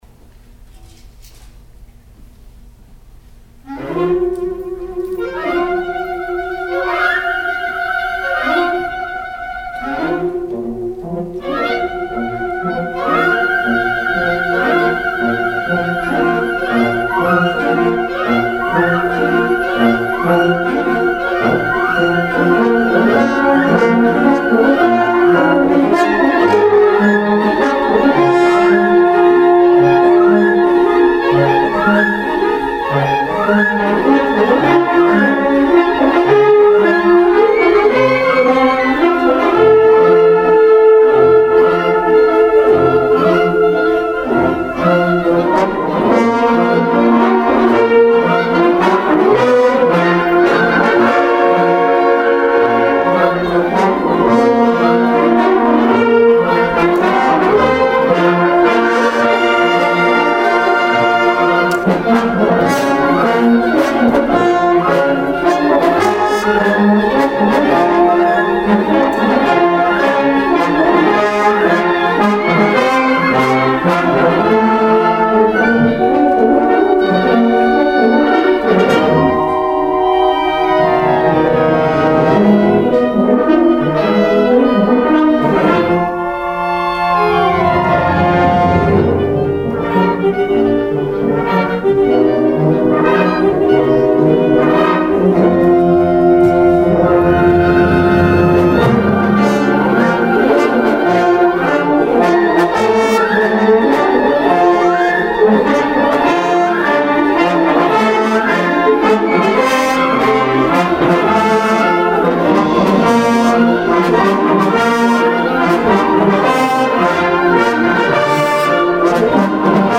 Winter Concert